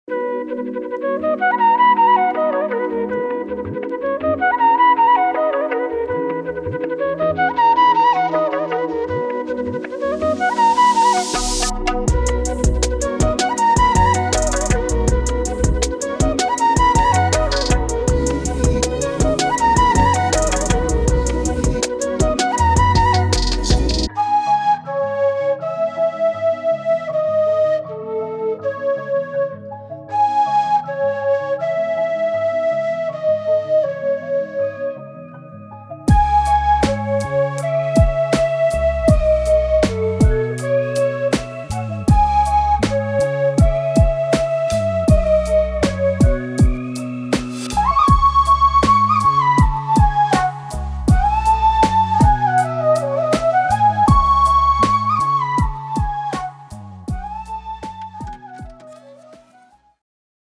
Indian